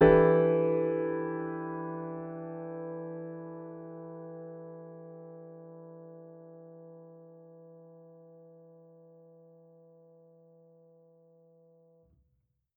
Index of /musicradar/jazz-keys-samples/Chord Hits/Acoustic Piano 1
JK_AcPiano1_Chord-Em11.wav